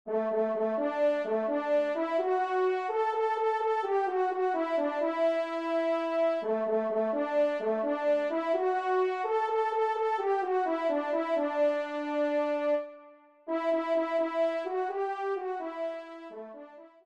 Trompe 1